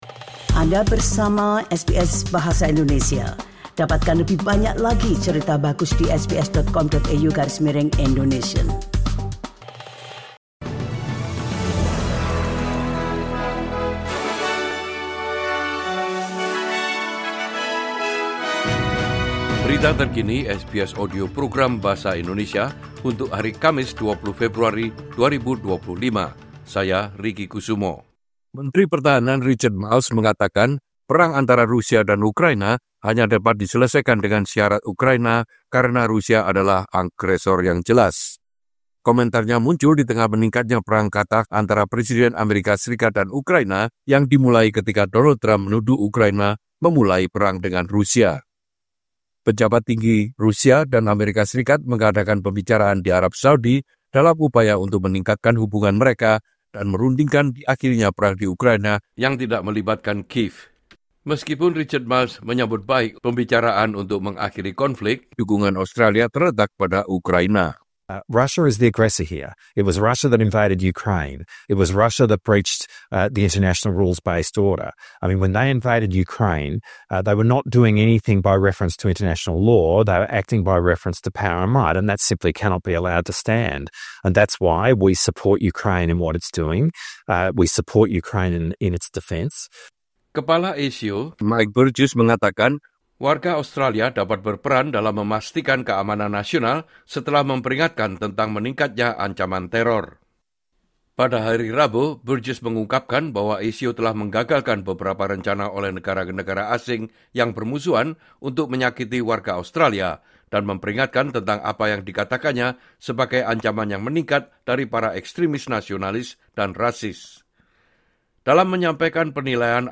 Latest News SBS Audio Program Bahasa Indonesia - February 20, 2025 - Berita Terkini SBS Audio Program Bahasa Indonesia - 20 Februari 2025